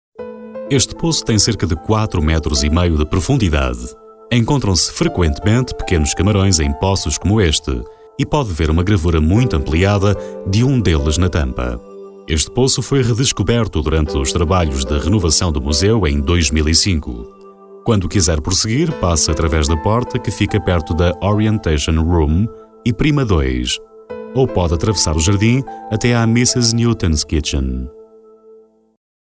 Portugiesischer Sprecher.
Sprecher portugiesisch.
Sprechprobe: Sonstiges (Muttersprache):